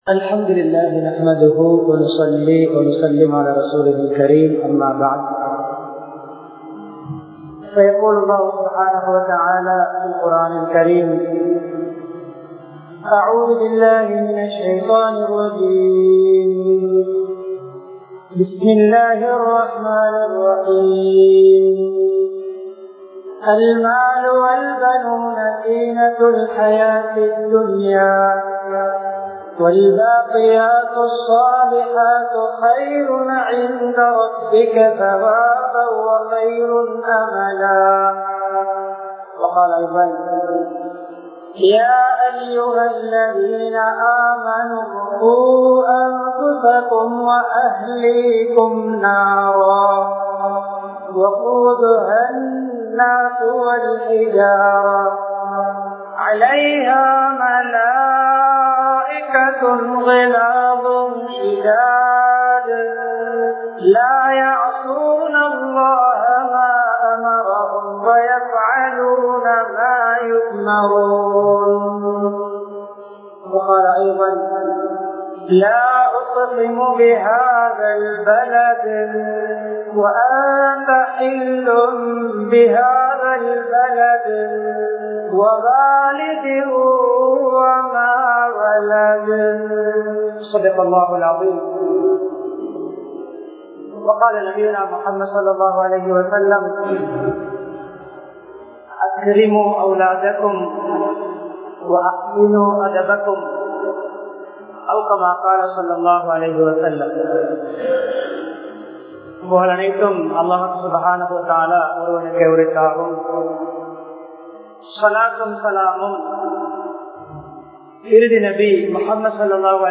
பிள்ளைகளின் ஒழுக்கமும் பெற்றோர்களும் | Audio Bayans | All Ceylon Muslim Youth Community | Addalaichenai
Polannaruwa, Sungavila Mubeen Jumua Masjith